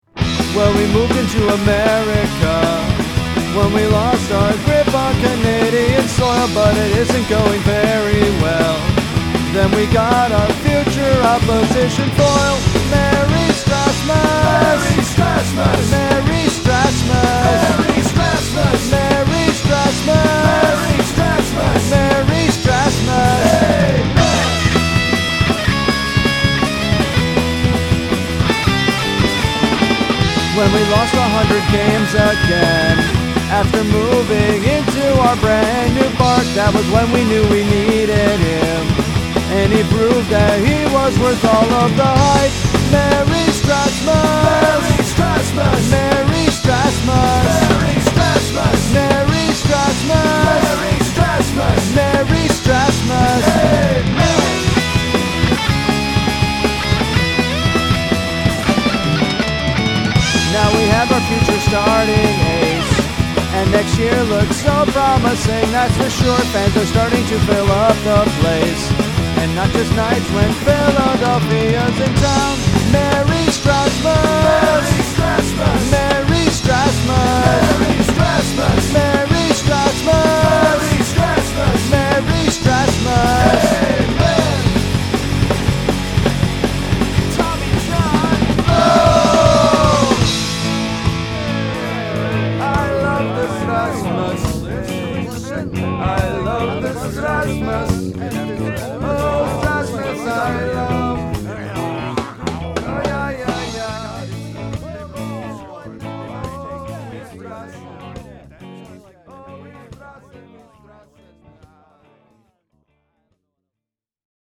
I channeled the DC Hardcore scene (a la Minor Threat and Bad Brains) and wrote a simple tune and played it just a little too fast. The recording process for this song proved to be a pretty good endurance test, particularly on the drums… The ending is also in tribute to Bad Brains, who repeatedly diverged into reggae from punk during the course of their career.